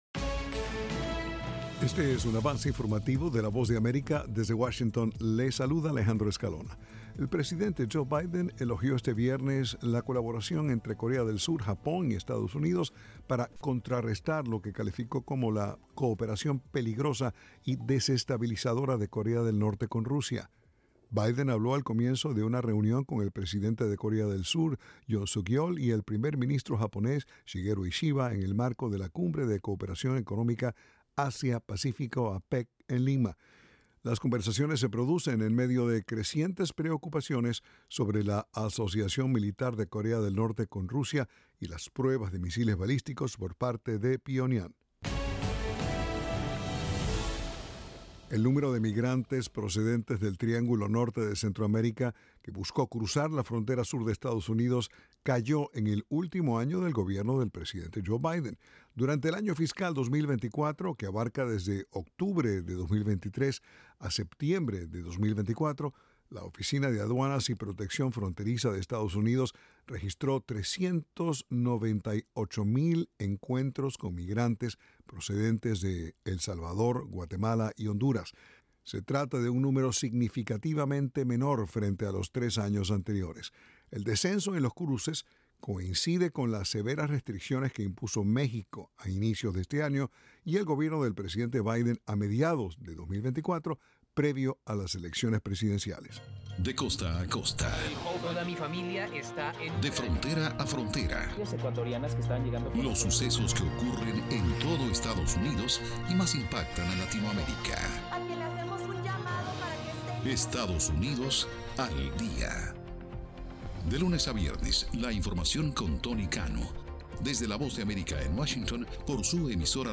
El siguiente es un avance informativo de la VOA.